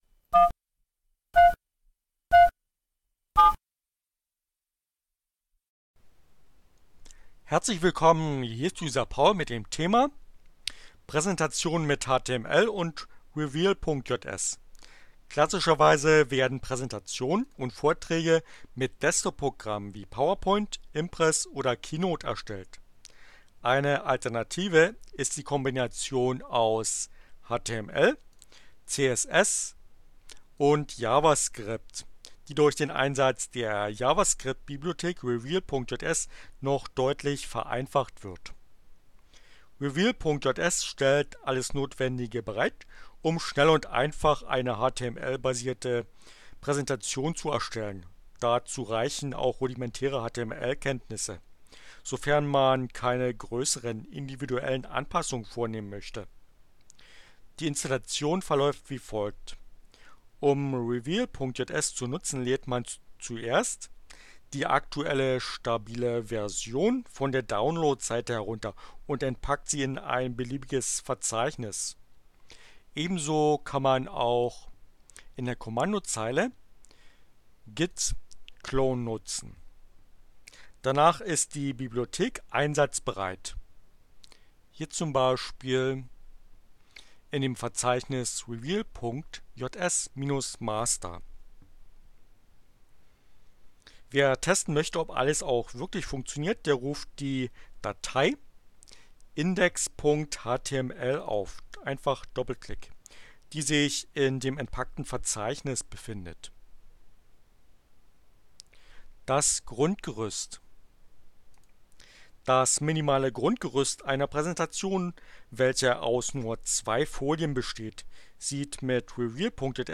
Tags: CC by-sa, Linux, Neueinsteiger, ohne Musik, screencast, HTML, Web, revealjs